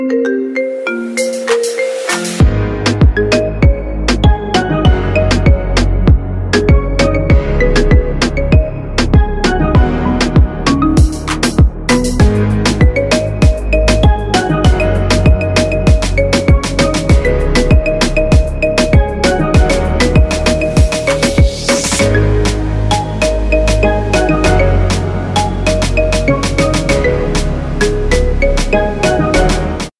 Categoría Marimba Remix